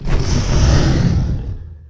ogg / general / combat / creatures / dragon / he / attack1.ogg
attack1.ogg